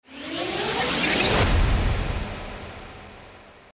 FX-1850-WIPE
FX-1850-WIPE.mp3